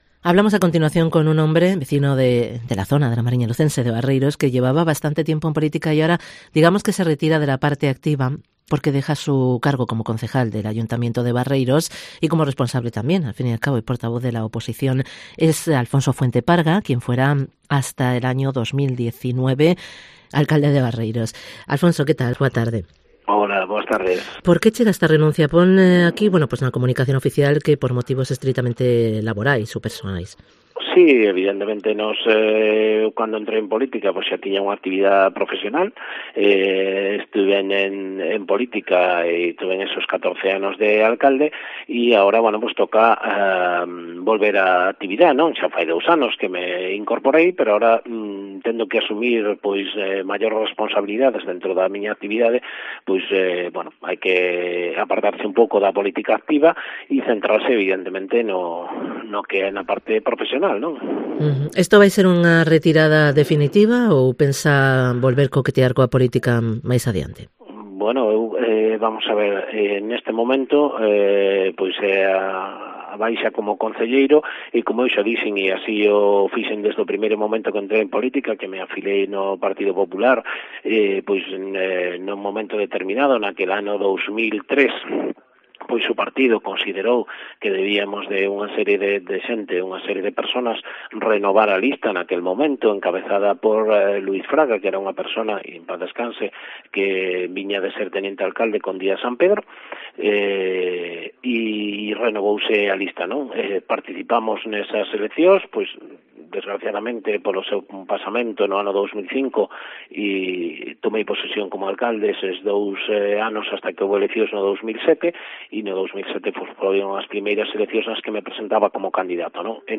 Entrevista con ALFONSO FUENTE, exalcalde de Barreiros